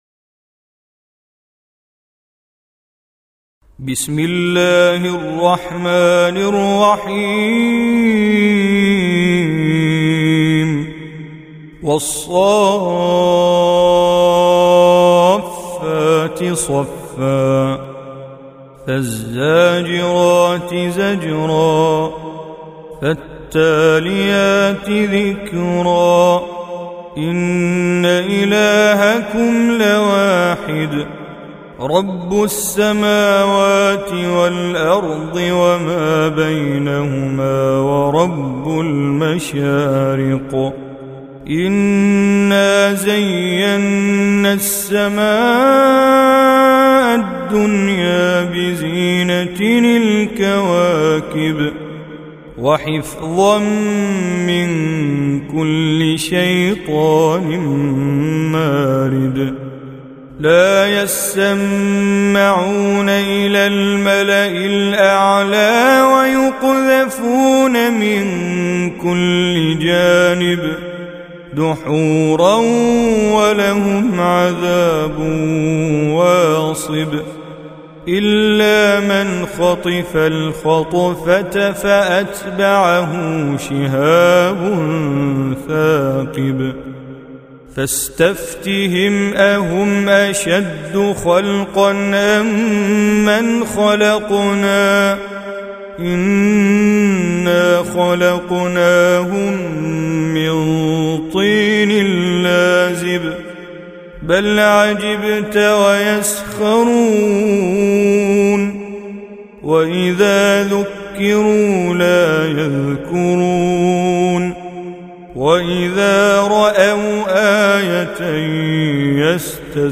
Surah Sequence تتابع السورة Download Surah حمّل السورة Reciting Mujawwadah Audio for 37. Surah As-S�ff�t سورة الصافات N.B *Surah Includes Al-Basmalah Reciters Sequents تتابع التلاوات Reciters Repeats تكرار التلاوات